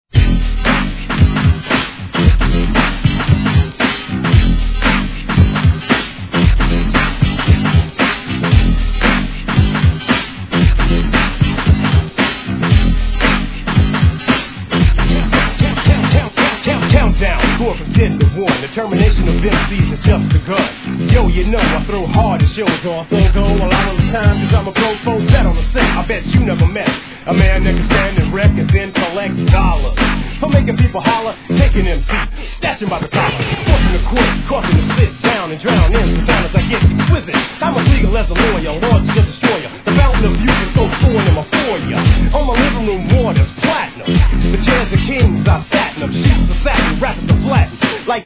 G-RAP/WEST COAST/SOUTH
疾走感あるFUNK BEATでいなたいRAPがはまります!